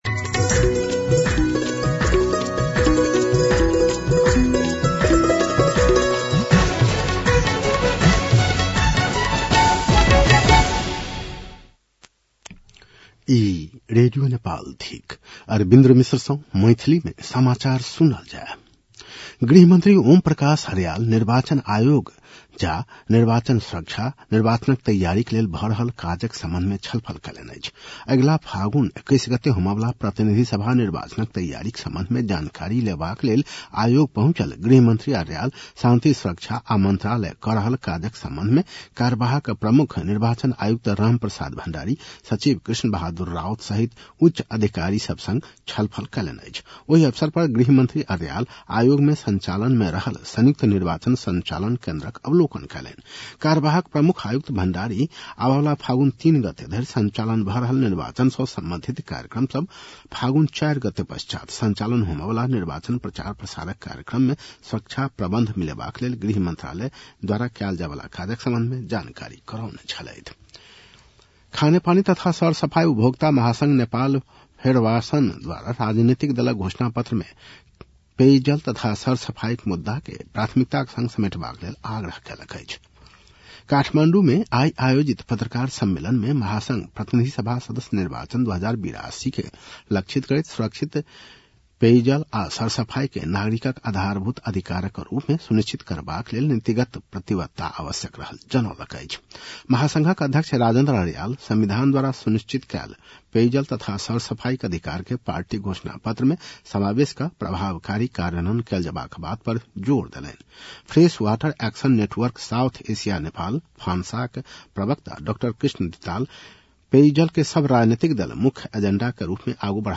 मैथिली भाषामा समाचार : २७ माघ , २०८२